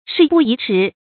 注音：ㄕㄧˋ ㄅㄨˋ ㄧˊ ㄔㄧˊ
讀音讀法：